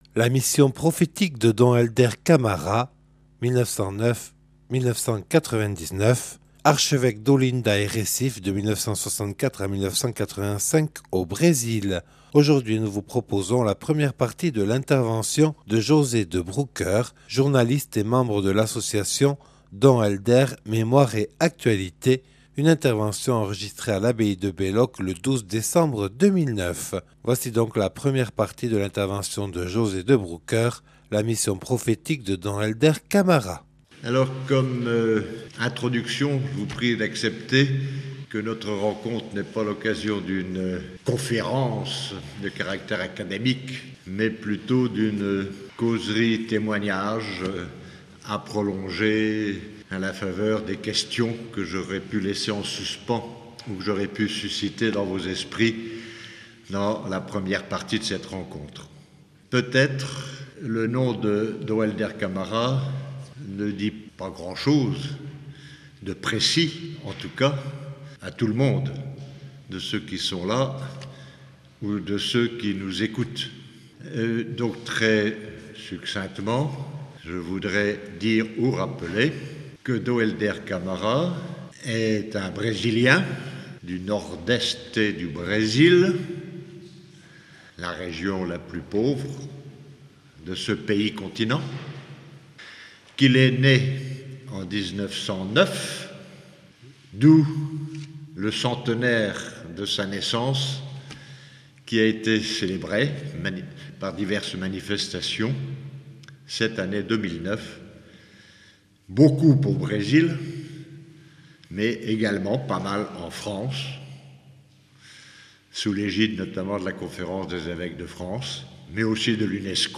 (Enregistré le 12/12/2009 à l’abbaye de Belloc).